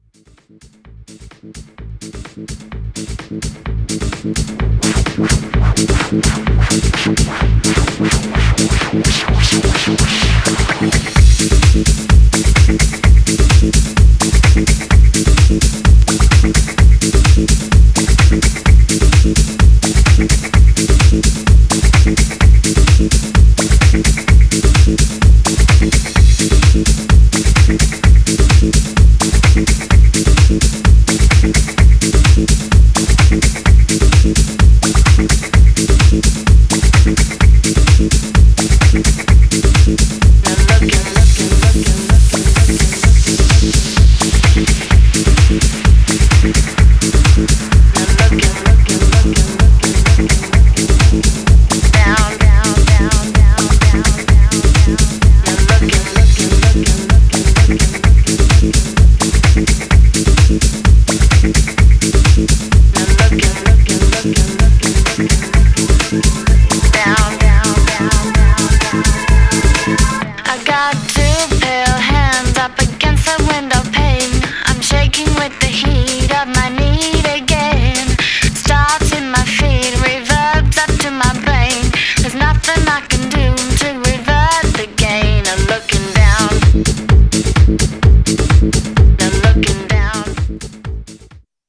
Styl: Progressive, Drum'n'bass, House